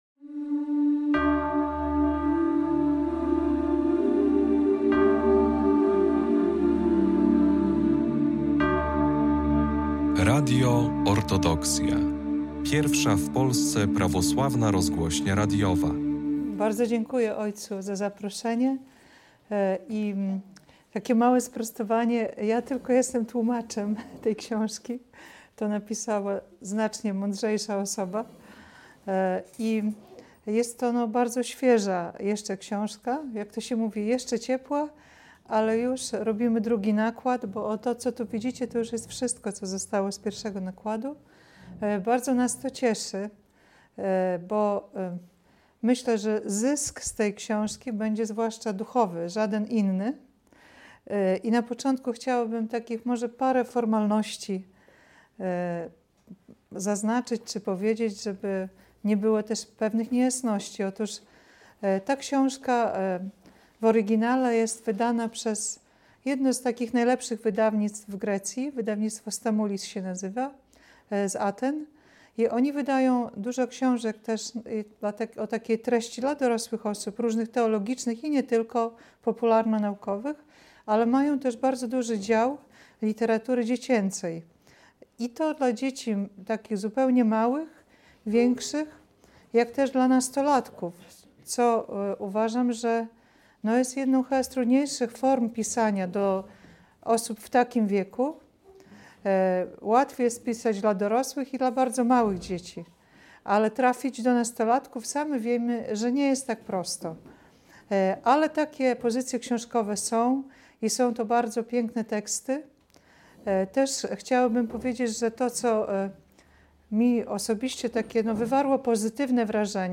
Relacja z prezentacji książki pt. "Z Miasta Ja do Miasta Ty". Spotkanie odbyło się 15 marca w parafii św. proroka Eliasza w Białymstoku.